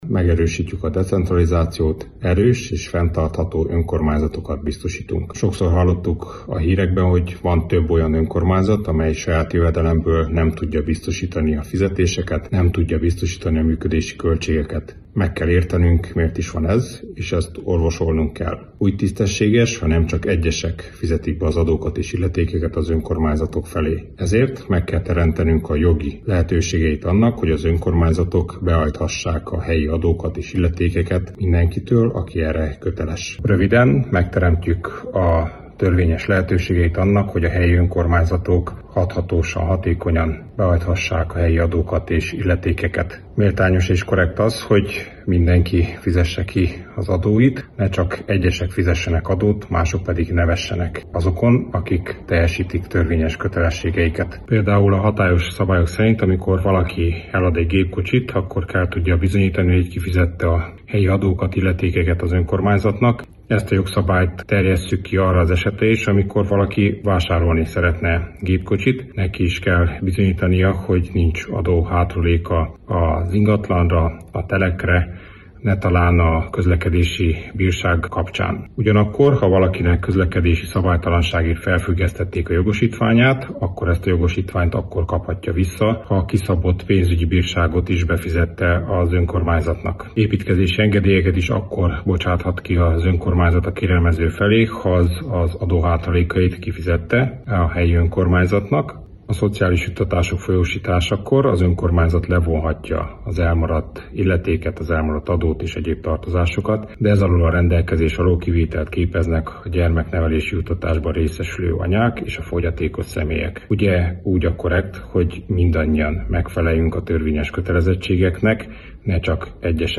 A tervezetről Cseke Attila fejlesztési miniszter számolt be.